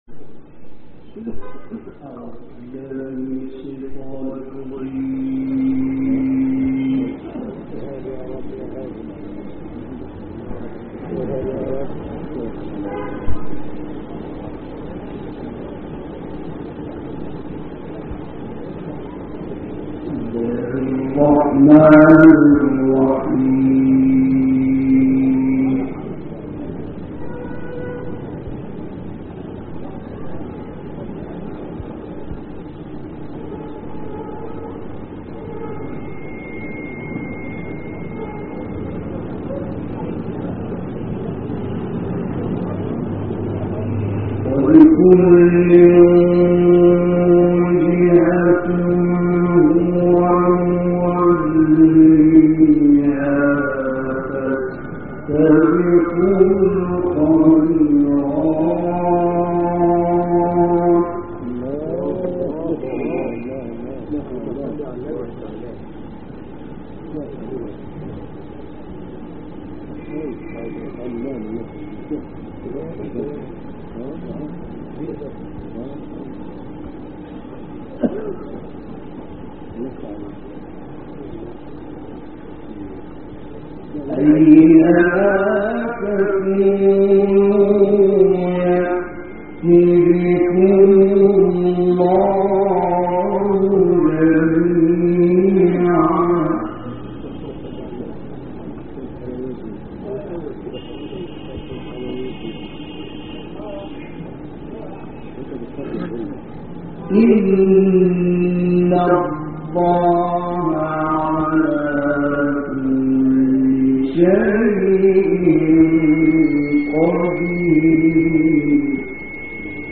گروه شبکه اجتماعی: تلاوت آیاتی از کلام الله مجید با صوت حمدی زامل و مصطفی اسماعیل که در محفل مشترک اجرا شده است، ارائه می‌شود.
محفل مشترک حمدی زامل و مصطفی اسماعیل
در این تلاوت یک ساعت و 24 دقیقه‌ای، حمدی زامل آیات 148 تا 164 سوره بقره، آیات 120 تا 128 سوره نحل و آیات 4 تا 7 سوره قصص را تلاوت کرده است، همچنین در انتهای این مجلس مصطفی اسماعیل به تلاوت آیات 1 تا 11 سوره اسرا می‌پردازد.